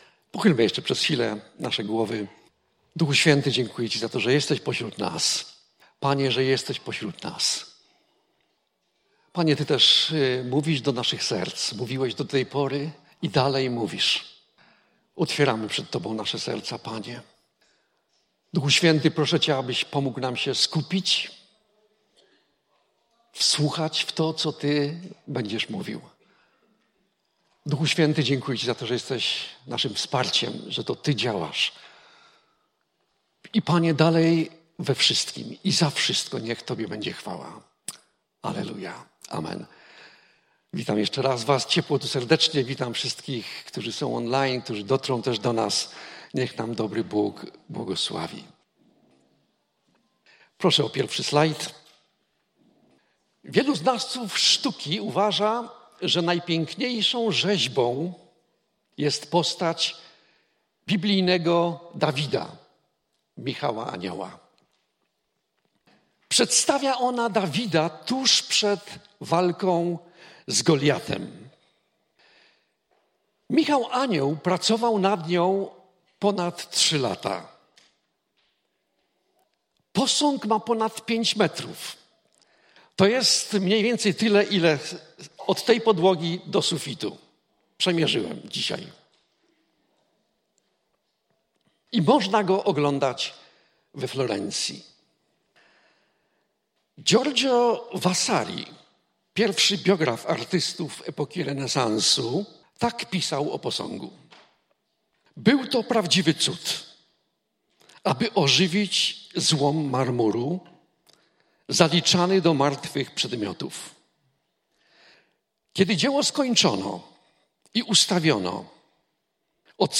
Pytania do przemyślenia po kazaniu: